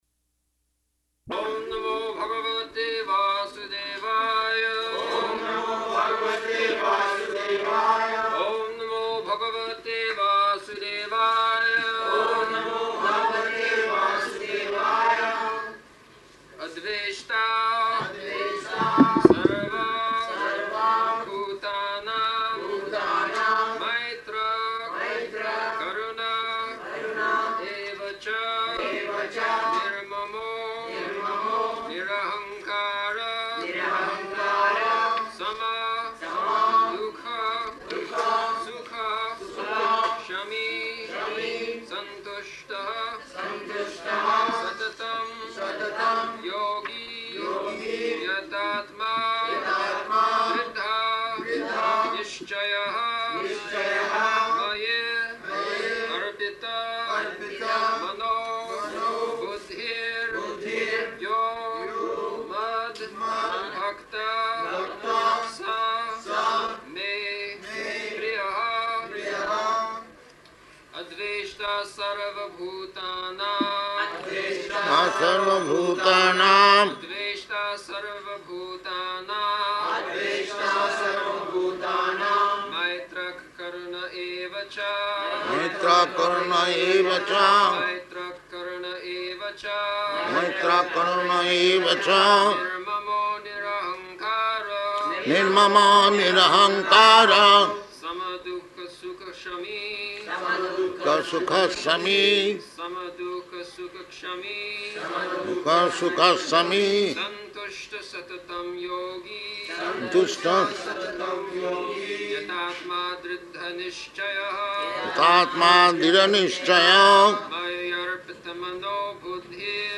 May 12th 1974 Location: Bombay Audio file
[Prabhupāda and devotees repeat]
[child crying] [aside:] That talking, stop.